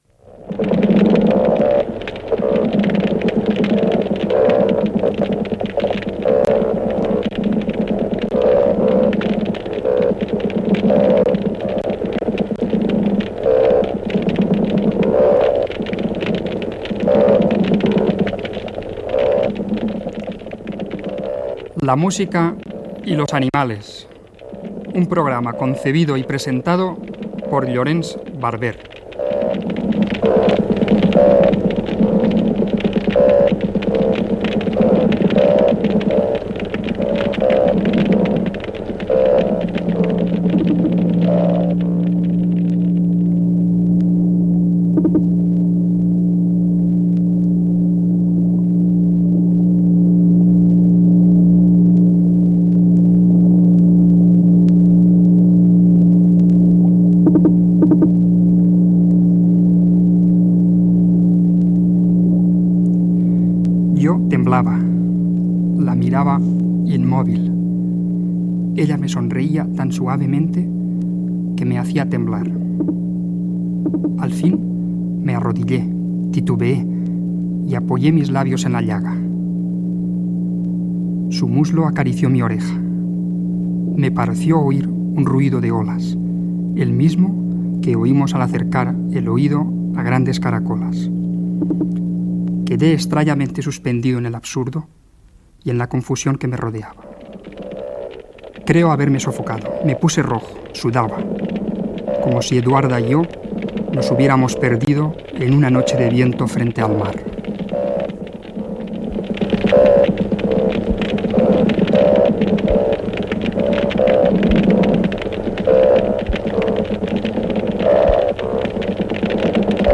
Careta del programa i espai dedicat a "El agua y sus habitantes" Gènere radiofònic Musical